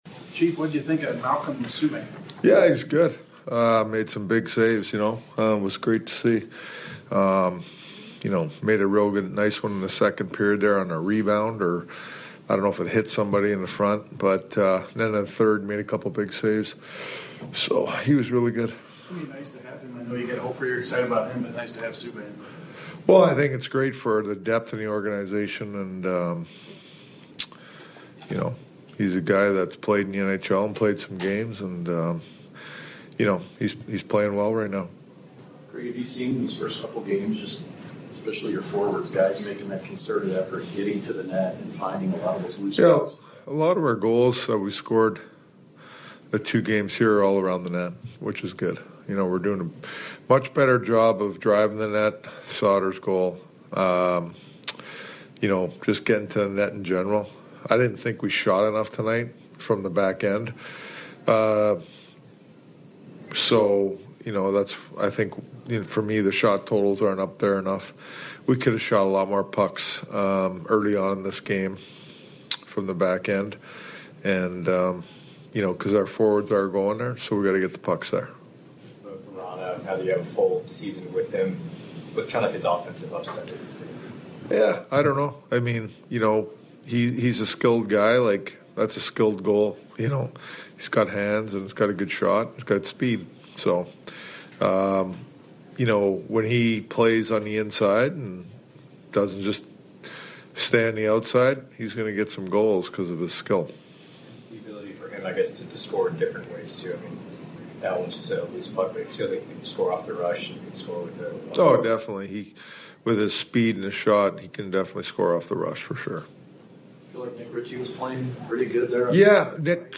short 3-min press conference from Coach Craig Berube